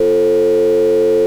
BUCHLA F2.wav